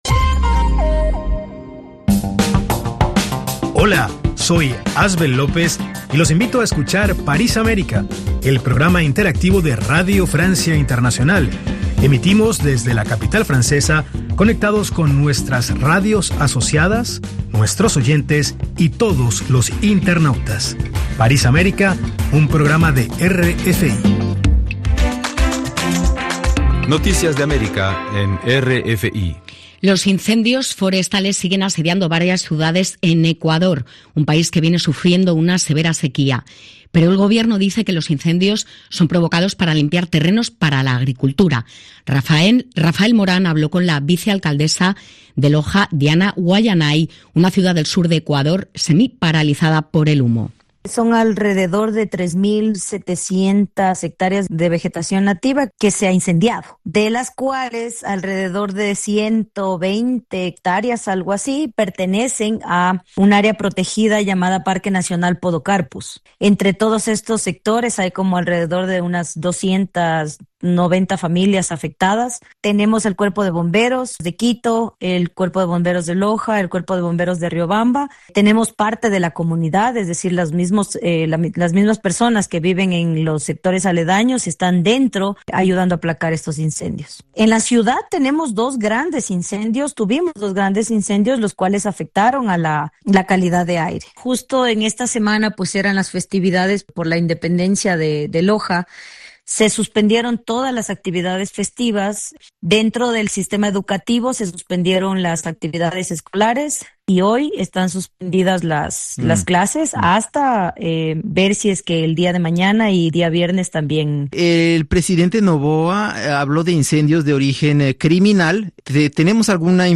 Noticieros